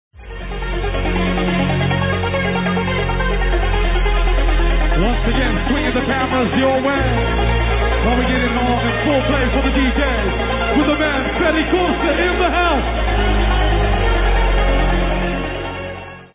live at matrixx